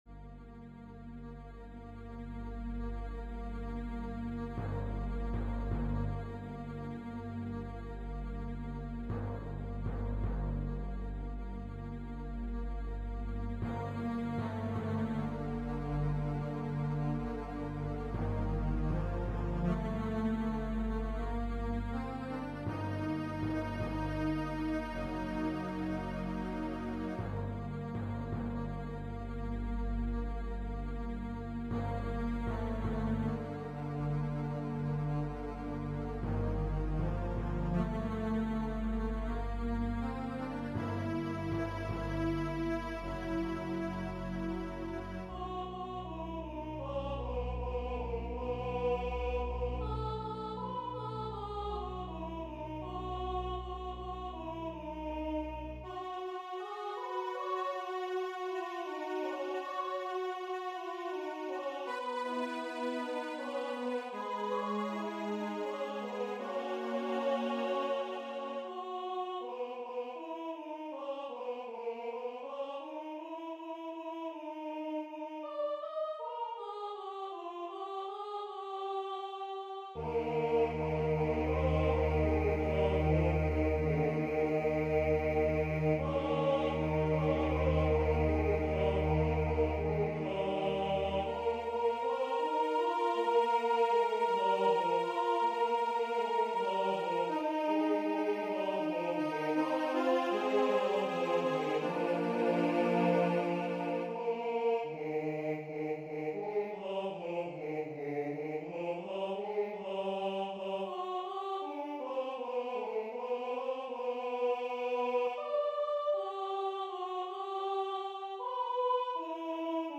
Number of voices: 4vv Voicing: SATB Genre: Sacred, Motet
Language: Latin Instruments: Orchestra
Score information: A4, 53 pages, 950 kB Copyright: Personal Edition notes: The MP3 file is a synthesized realisation of the music.
String-orchestra, 3 Horns in F, Timpani